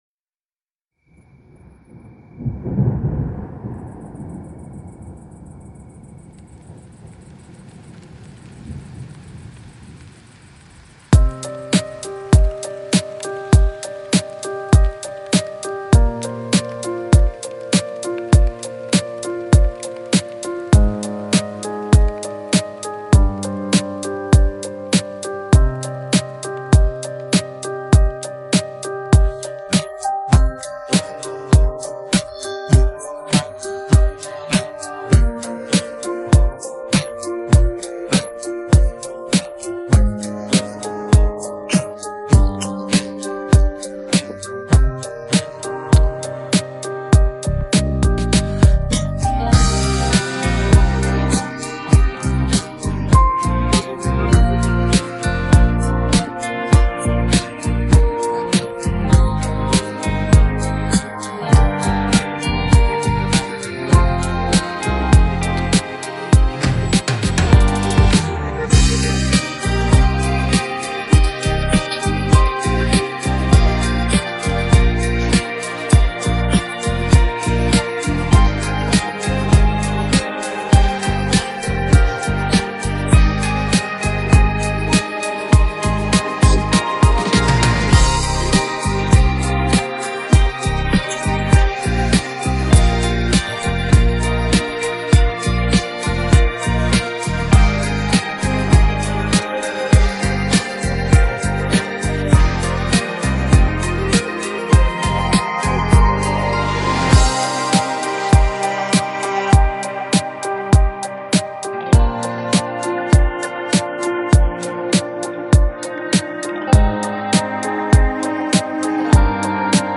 پخش نسخه بی‌کلام
download-cloud دانلود نسخه بی کلام (KARAOKE)